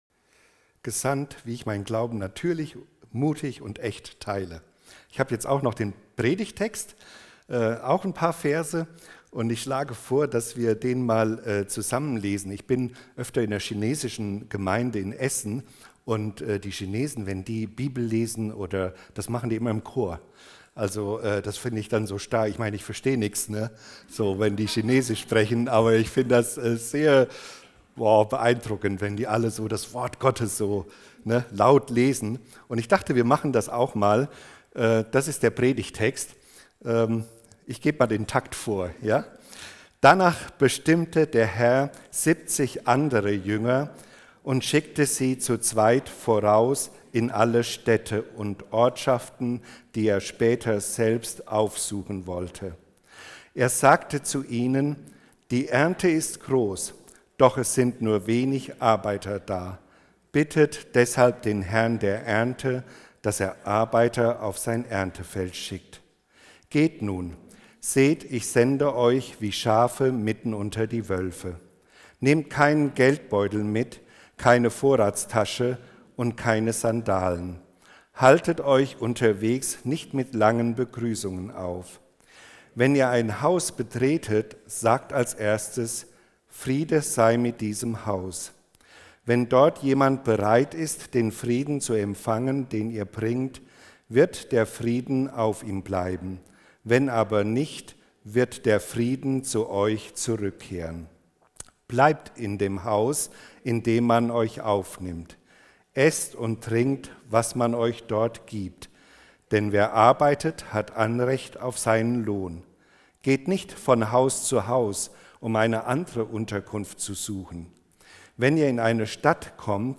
„Dranbleiben an Jesus“ Predigt